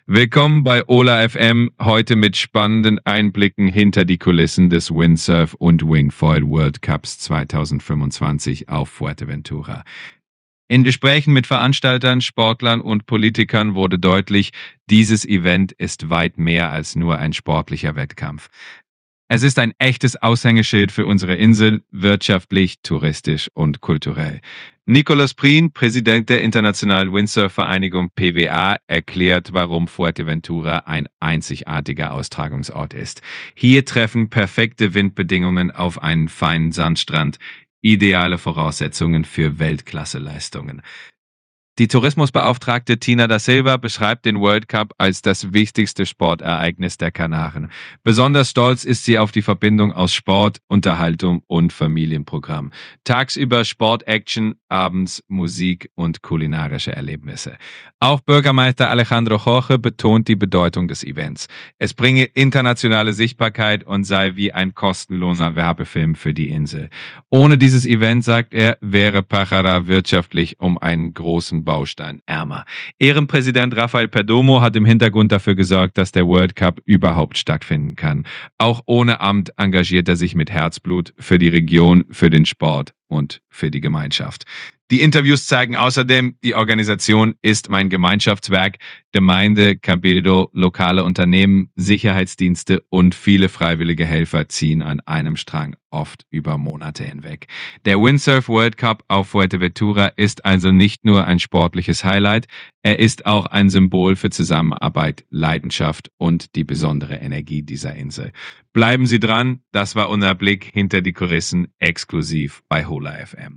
Info: In dieser Zusammenfassung kommen wichtige Stimmen zu Wort
Der Windsurf- und Wingfoil World Cup 2025 begeistert nicht nur Sportfans – er stärkt die Region Pájara wirtschaftlich, kulturell und touristisch. Exklusive Interviews mit Entscheidern und Beteiligten zeigen: Dieses Event ist ein Gemeinschaftsprojekt mit internationalem Strahlwert.